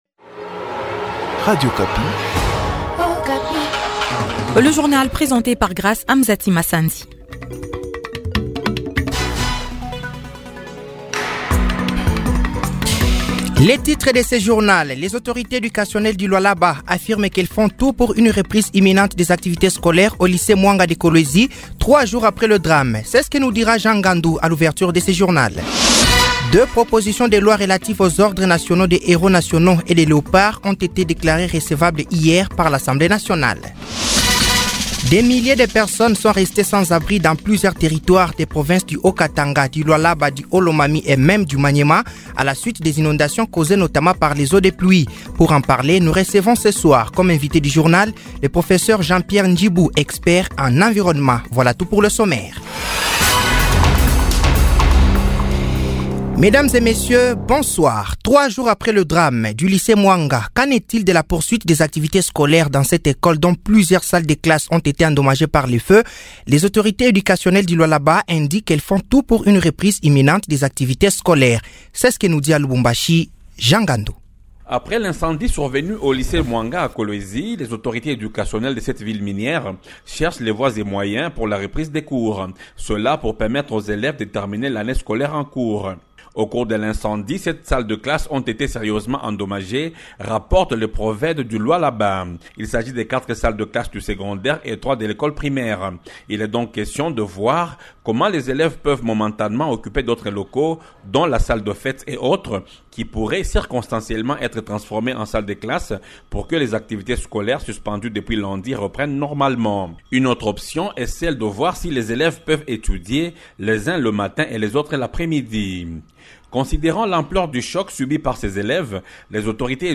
Journal français de 18h de ce mercredi 26/04/2023